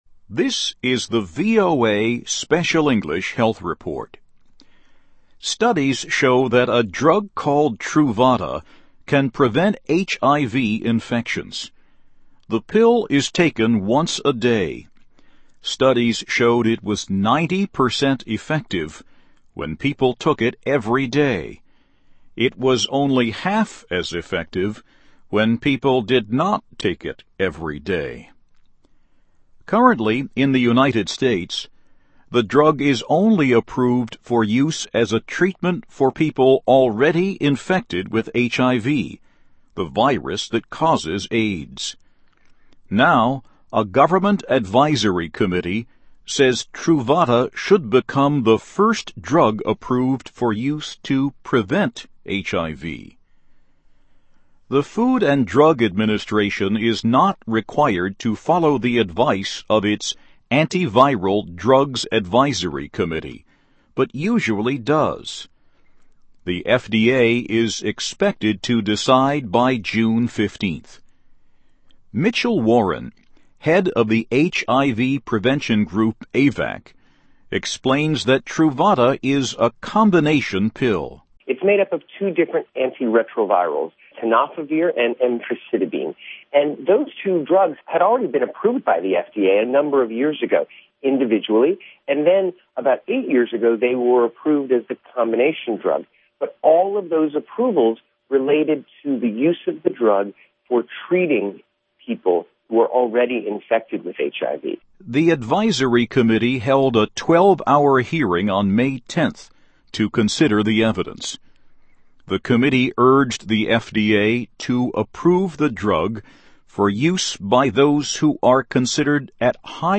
The Food and Drug Administration is expected to make a decision about Truvada, a daily pill, by June 15 | HEALTH REPORT